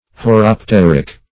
horopteric - definition of horopteric - synonyms, pronunciation, spelling from Free Dictionary Search Result for " horopteric" : The Collaborative International Dictionary of English v.0.48: Horopteric \Hor`op*ter"ic\, a. (Opt.) Of or pertaining to the horopter.